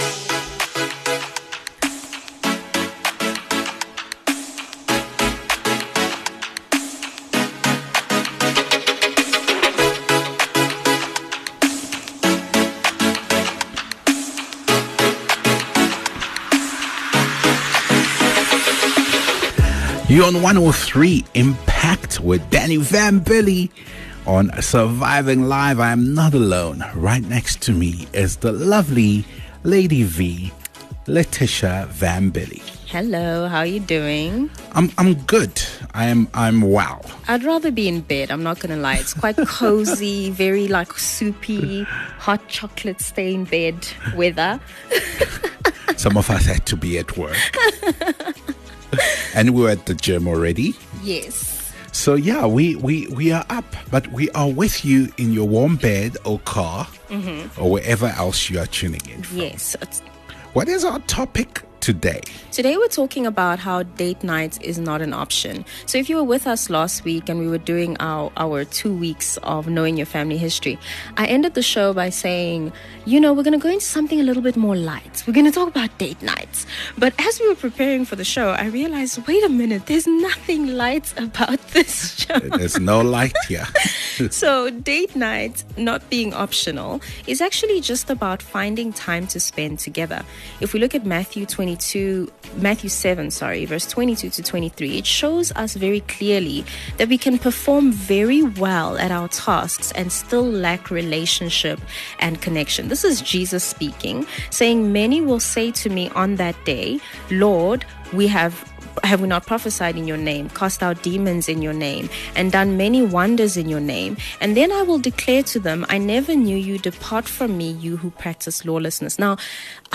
Live Show 44 MIN Download